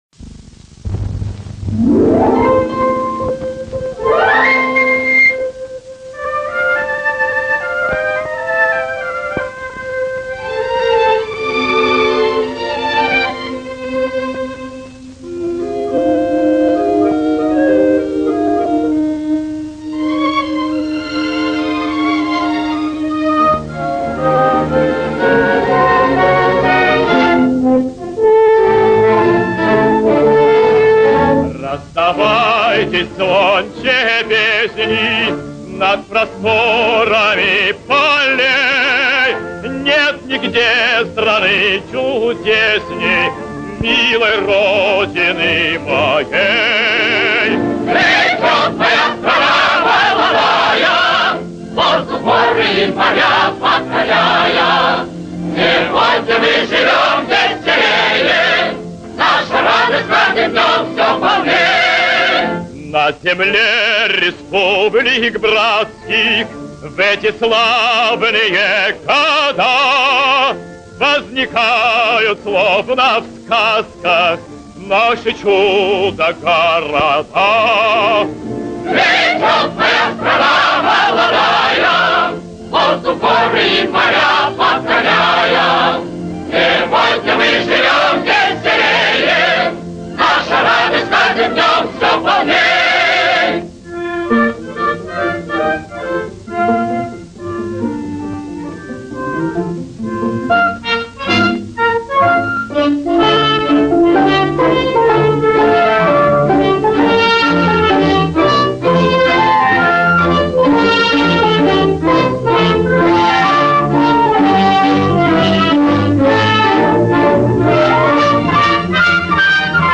Песня из довоенного мультфильма